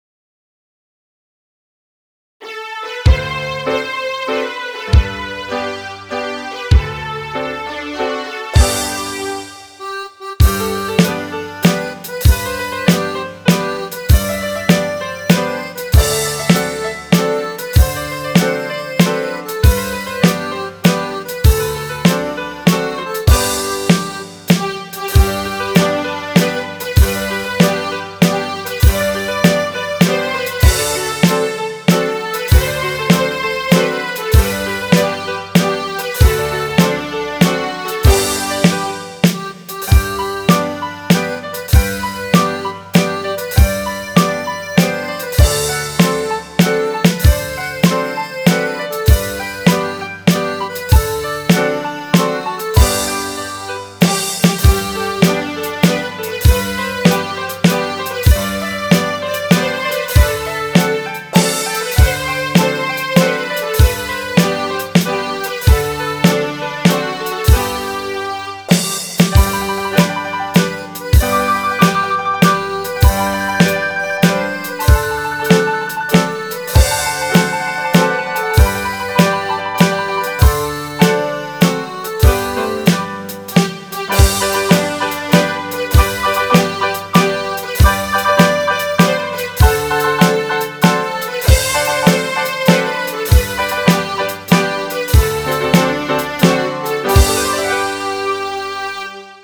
Midi File, Lyrics and Information to Clementine